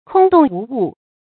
注音：ㄎㄨㄙ ㄉㄨㄙˋ ㄨˊ ㄨˋ
空洞無物的讀法